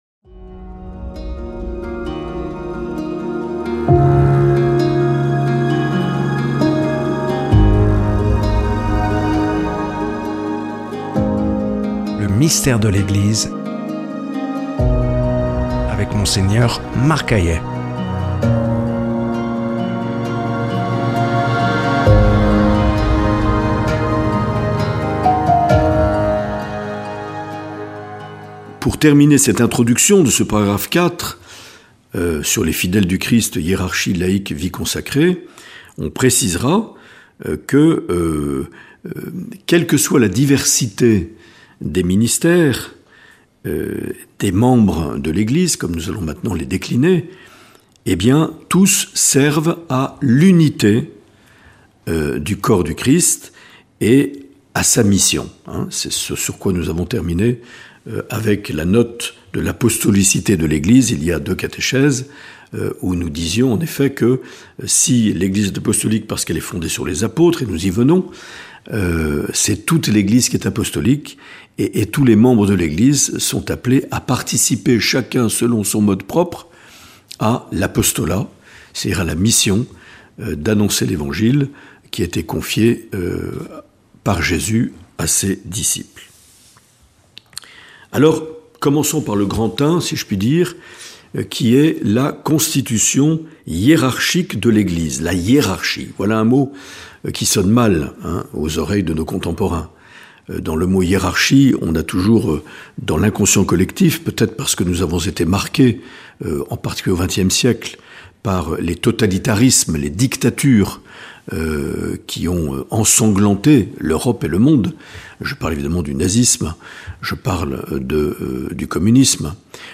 Mgr Marc Aillet nous propose une série de catéchèses intitulée "Le Mystère de l’Eglise" notamment à la lumière de la constitution dogmatique "Lumen Gentium" du concile Vatican II